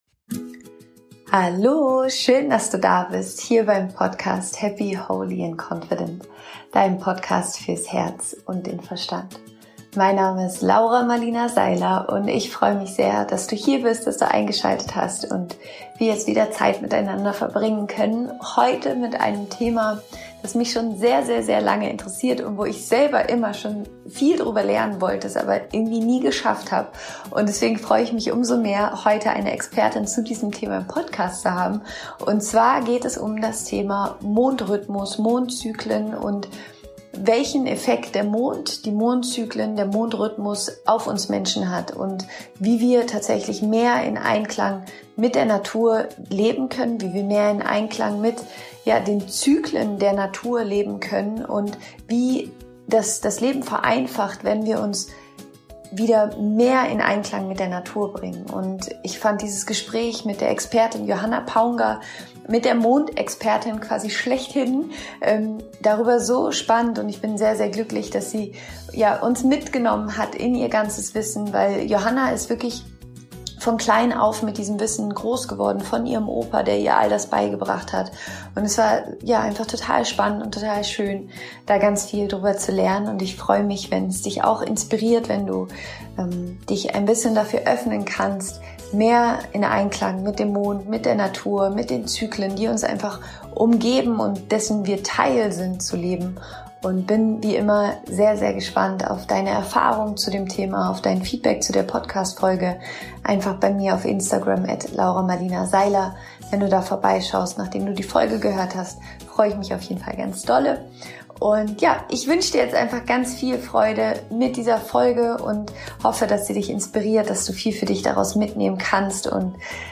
Ich freu mich sehr heute ein Interview mit der Mondexpertin Johanna Paungger mit dir teilen zu können.